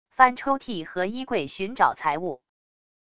翻抽屉和衣柜寻找财物wav音频